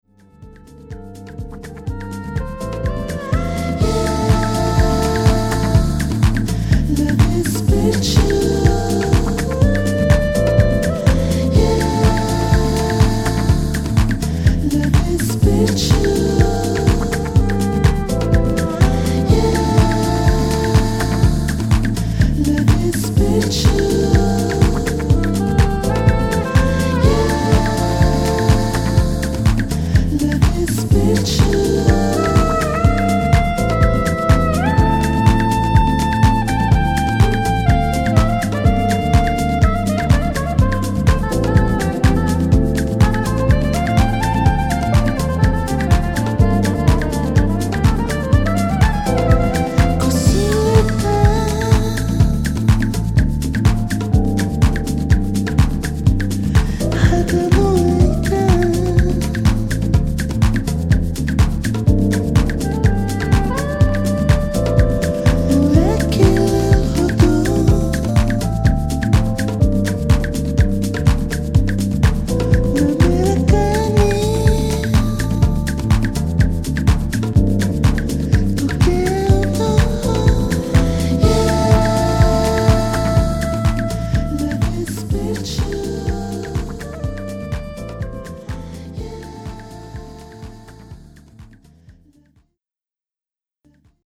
Vocal Mix